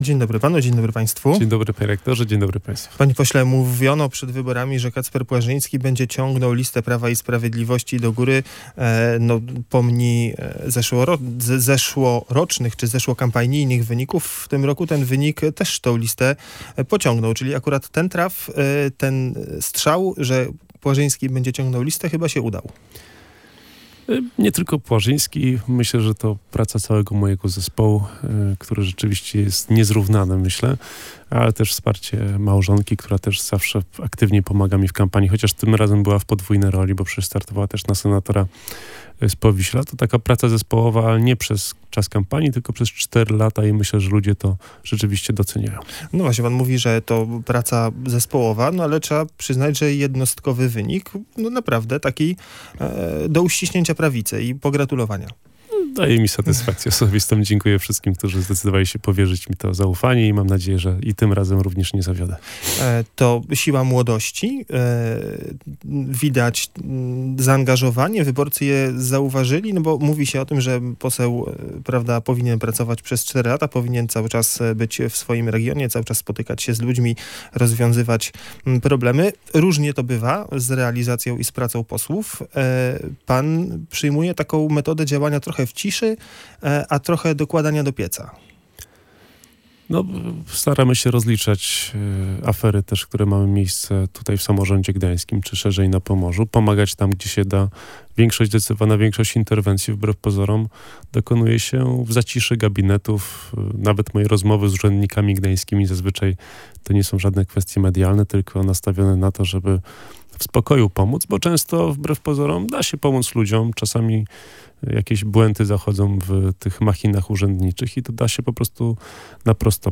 Wyobrażam sobie scenariusz, w którym do Sopotu czy Pruszcza Gdańskiego wysyła się jakiegoś sprawnego urzędnika z Warszawy, żeby zadbał o to, by maszyna urzędnicza działała sprawnie – mówił w Radiu Gdańsk Kacper Płażyński, pomorski poseł Prawa i Sprawiedliwości.